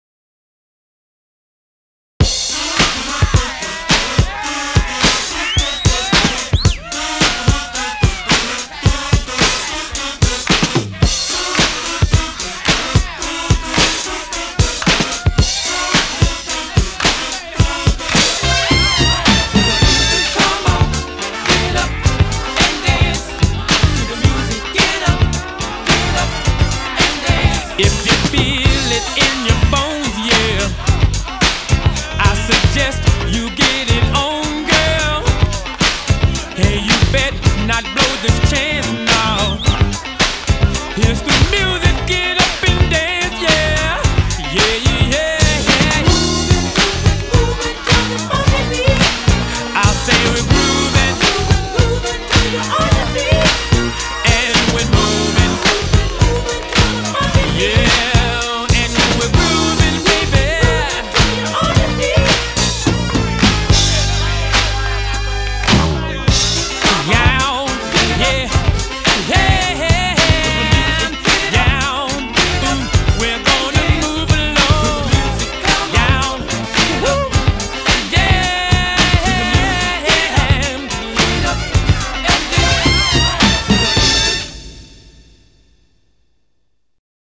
BPM 109
Audio Quality Perfect (High Quality)